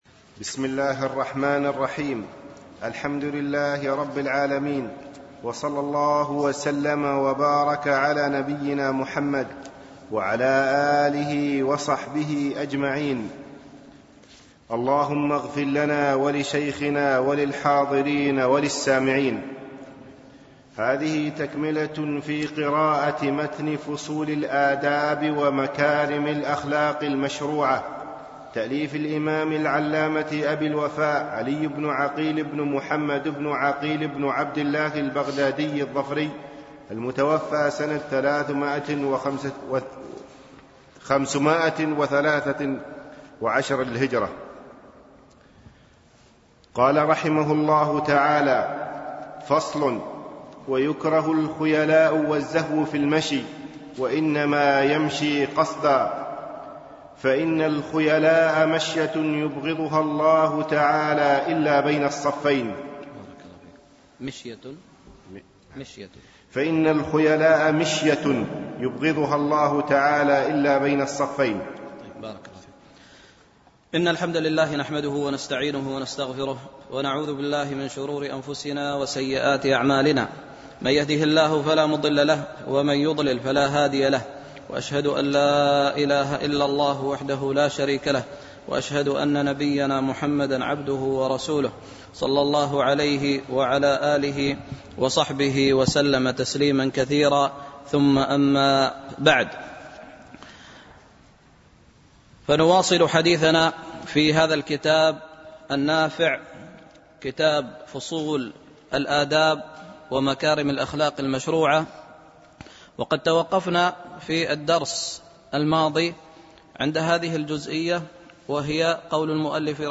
دورة الإمام مالك العلمية الثالثة بدبي
دروس مسجد عائشة